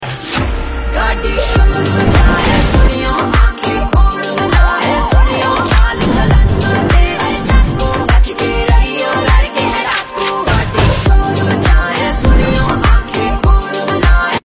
Her powerful voice and expressive delivery
including hip-hop, pop, and Punjabi folk